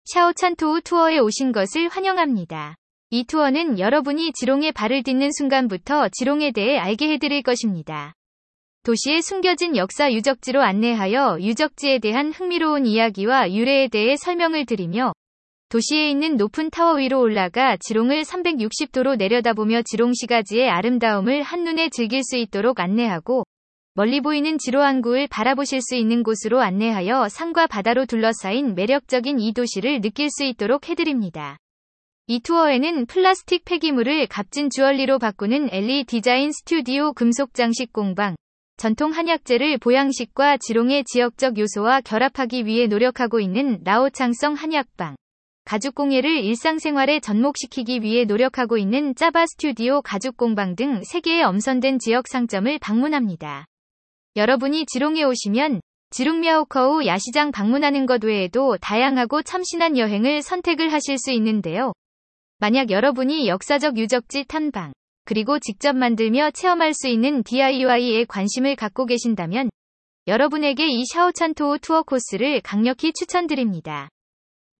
이 경로의 오디오 가이드 1분 무료 체험